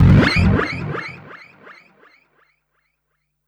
35SFX 02  -L.wav